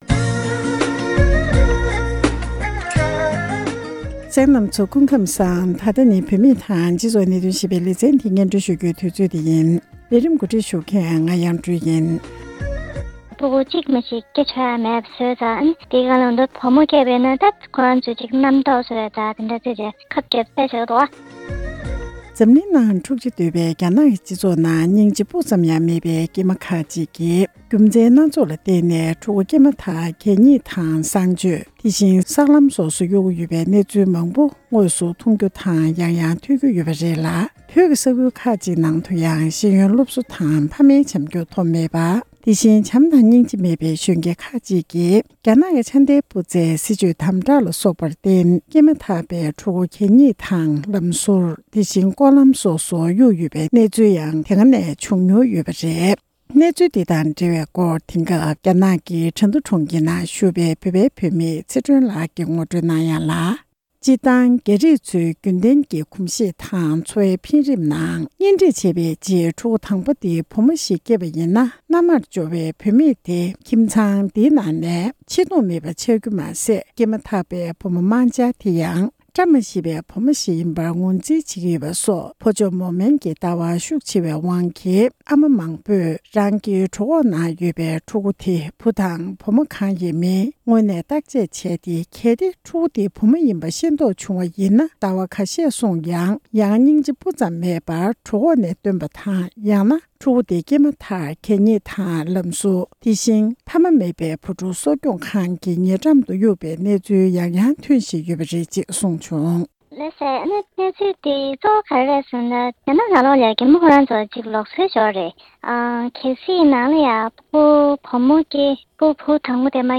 འབྲེལ་ཡོད་ཁག་ཅིག་ལ་བཅར་འདྲི་ཞུས་ཏེ་གནས་ཚུལ་ཕྱོགས་སྒྲིག་ཞུས་པ་ཞིག་ལ་གསན་རོགས་གནང་༎